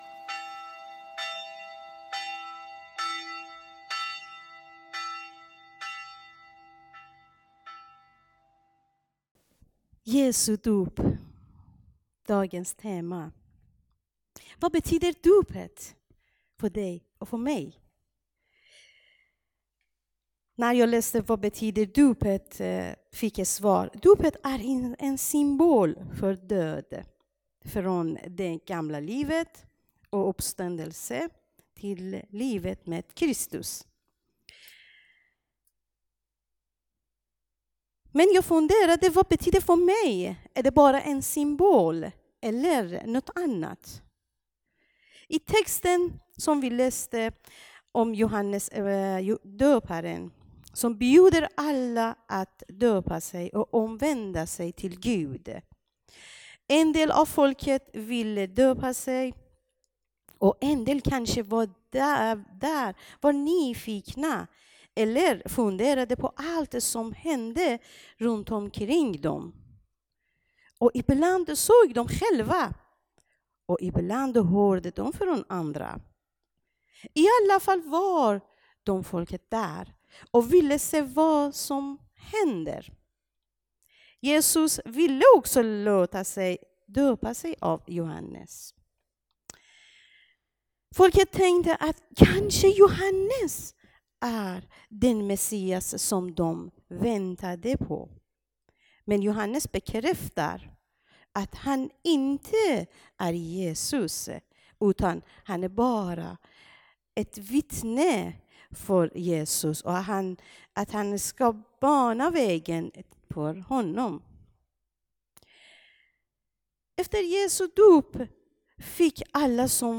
Dagens tema är Jesu dop. Predikan